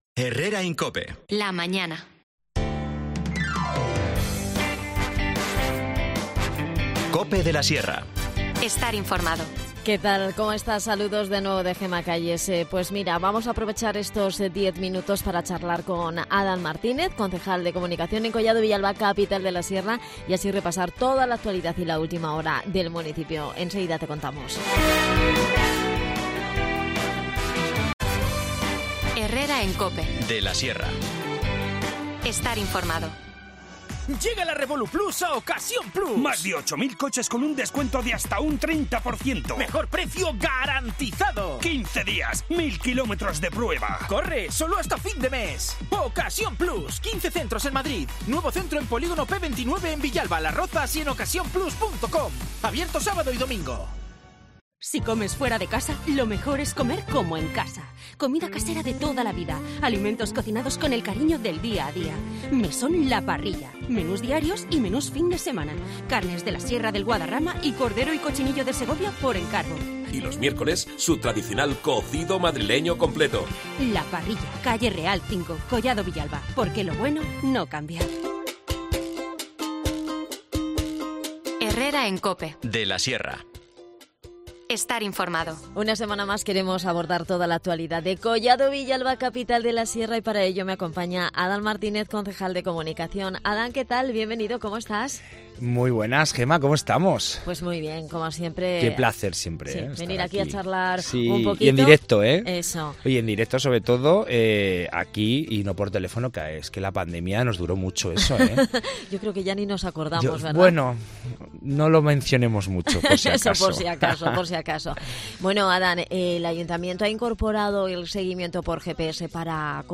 Adan Martínez, concejal de Comunicación en Collado Villalba, Capital de la Sierra, nos visita para hablarnos del seguimiento por GPS que están llevando a cabo para el control de las poblaciones de jabalíes cercanas al municipio y así poder adoptar diversas medidas preventivas.